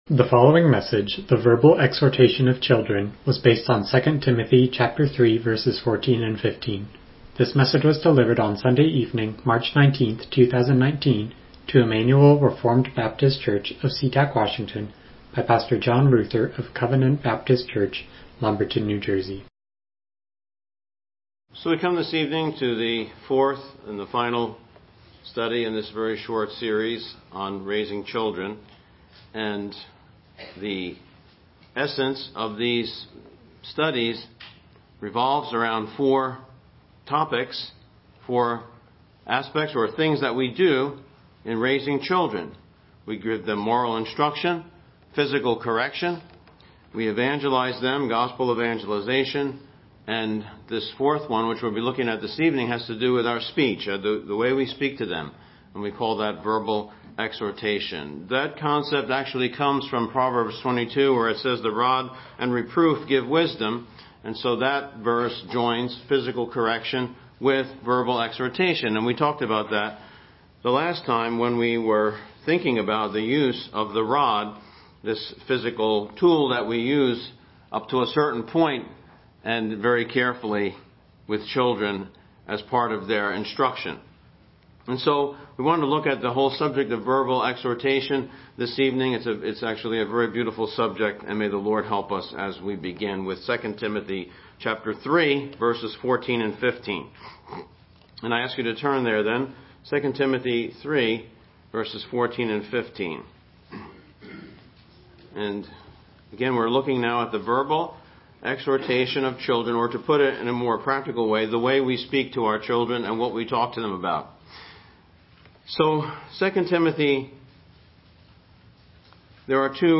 Passage: 2 Timothy 3:14-15 Service Type: Evening Worship